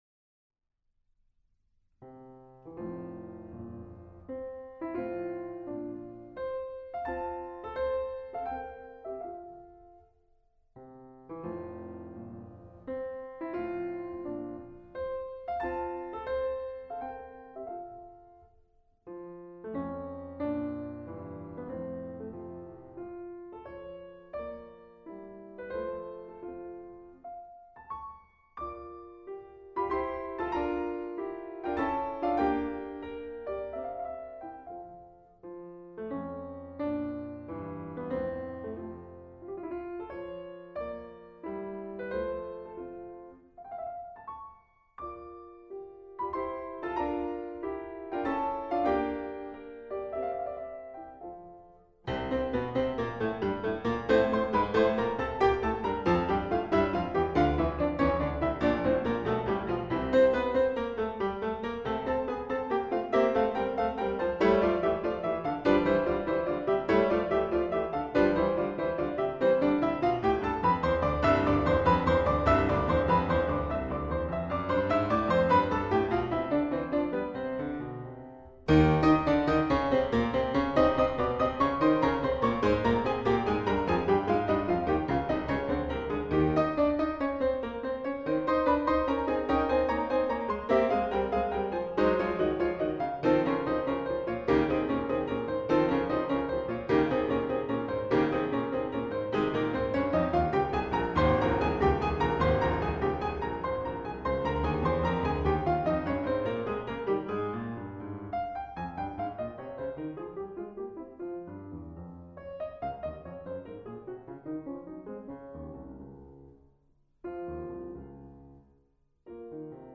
Piano Sonata #22 In F Op. 54 (Schirmer Performance Edition) - BEETHOVEN LUDWIG VAN - LMI Partitions